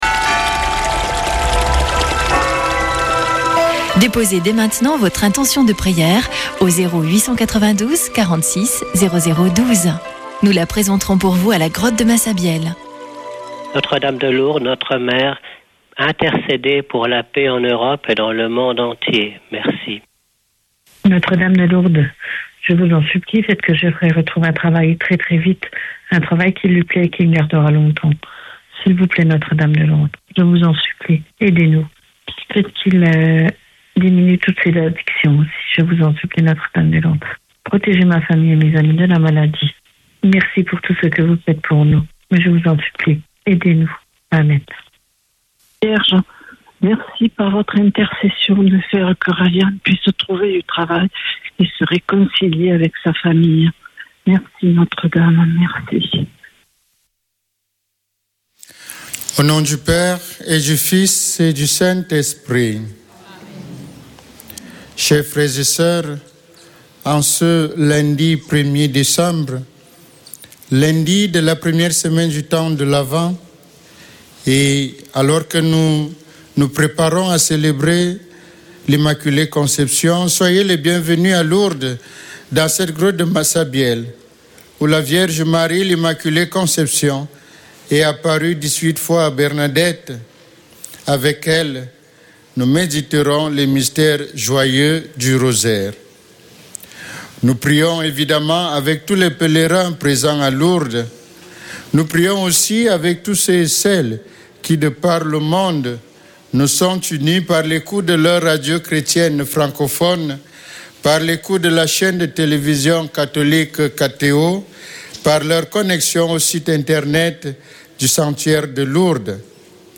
Chapelet de Lourdes du 01 déc.
Une émission présentée par Chapelains de Lourdes